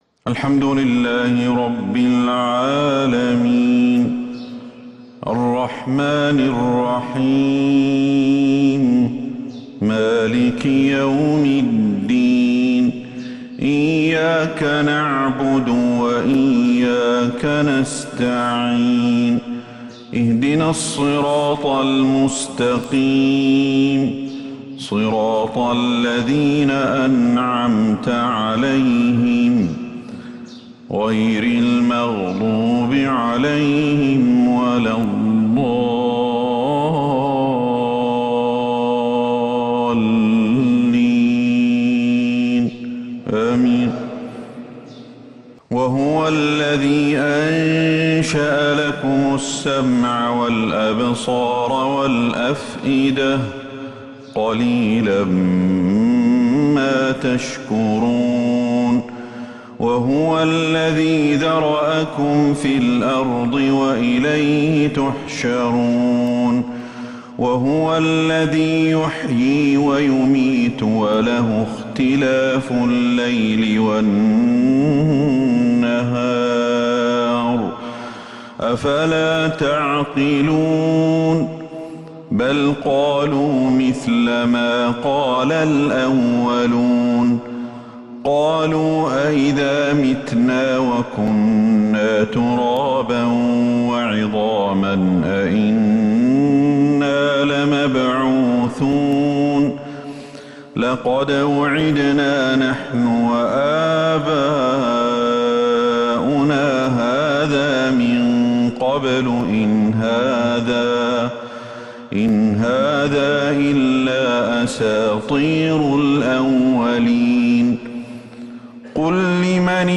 فجر الثلاثاء 5 شعبان 1443هـ خواتيم سورة {المؤمنون} > 1443 هـ > الفروض - تلاوات الشيخ أحمد الحذيفي